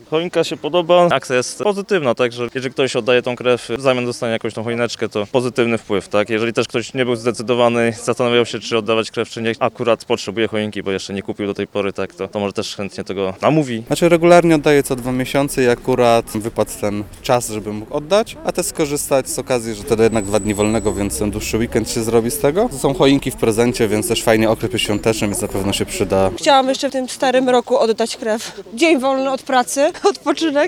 – Akcja zachęca do oddawania krwi – mówią honorowi krwiodawcy, którzy wzięli udział w akcji: